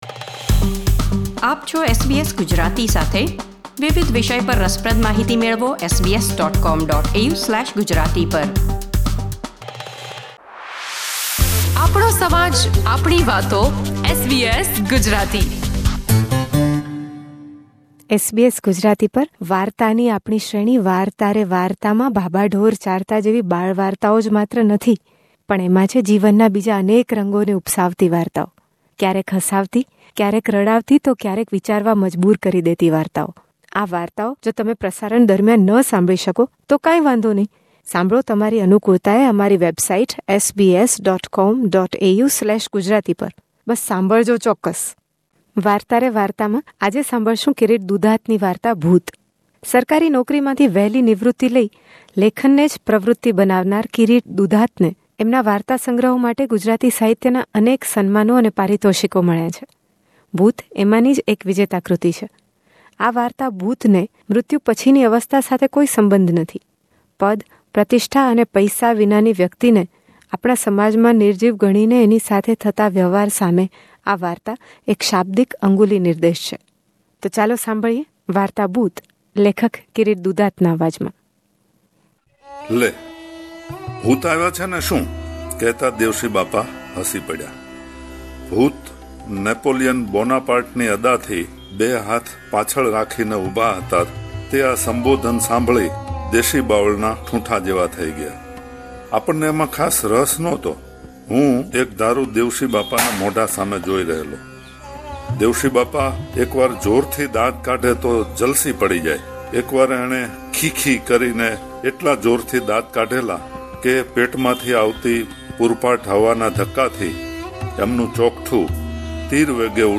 એ માટે સાંભળો આ વાર્તા લેખકના પોતાના અવાજમાં.